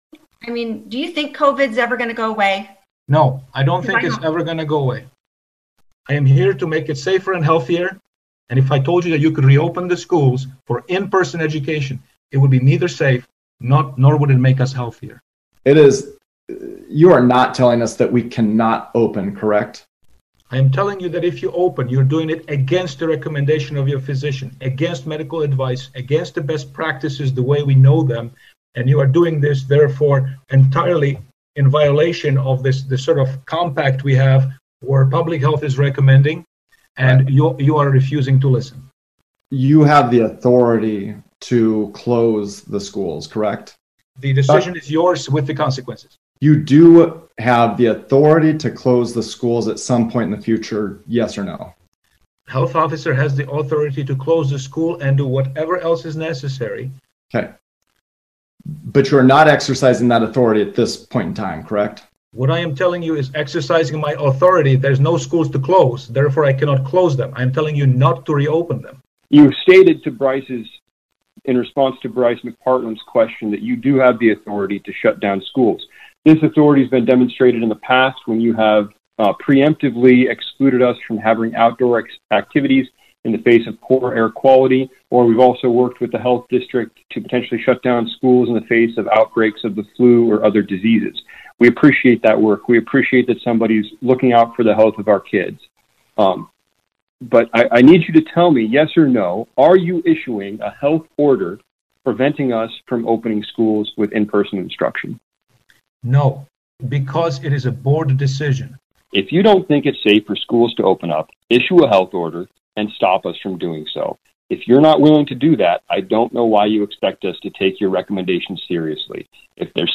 Closing Comments from Board Chairman Elliot Goodrich